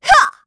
Isolet-Vox_Attack2_kr.wav